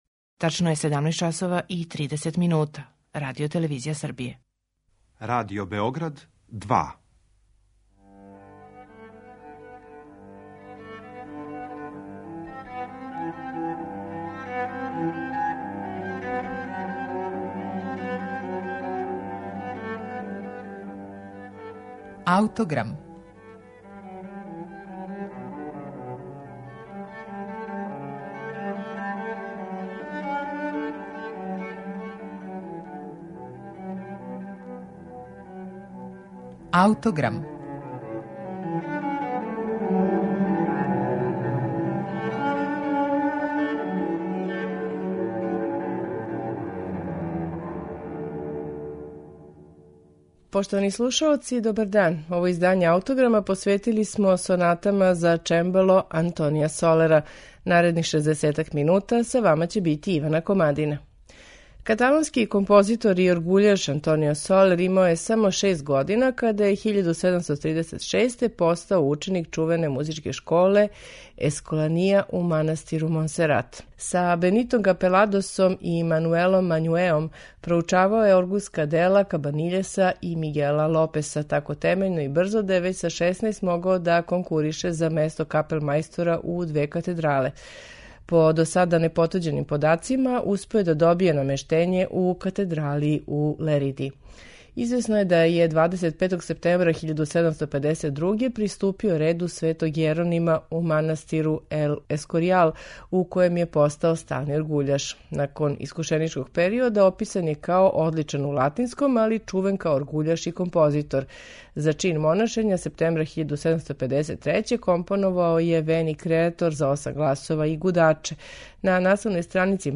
Емисију смо посветили сонатама за чембало Антонија Солера
чембалисте